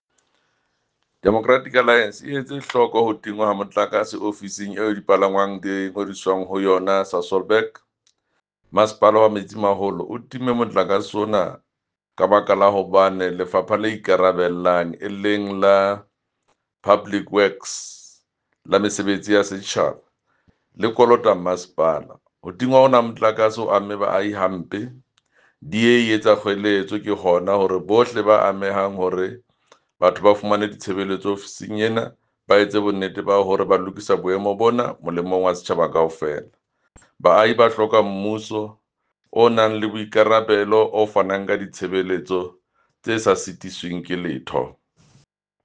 Sesotho soundbites by Jafta Mokoena MPL